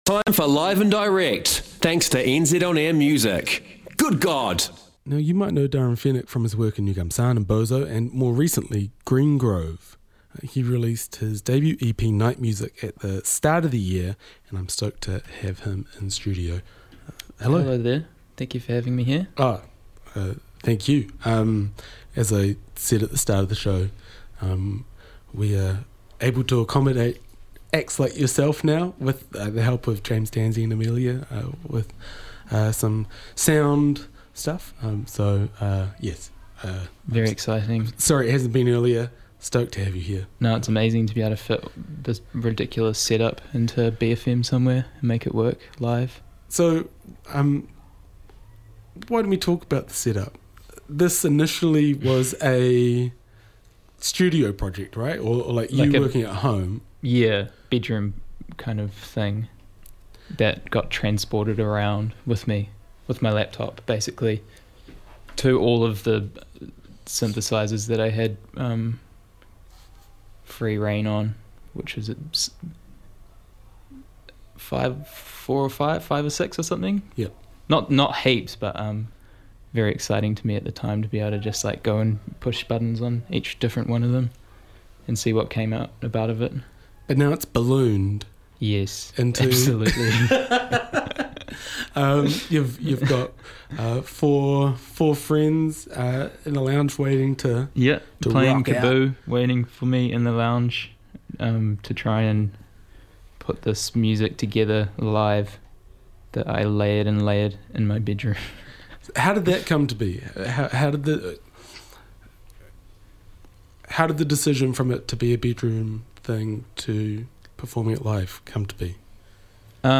in studio for a live performance